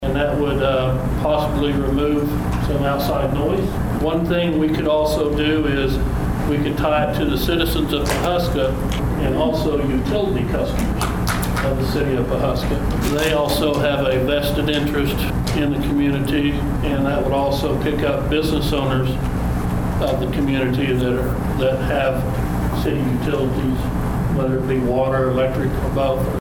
Tolson addressed that during Tuesday's meeting.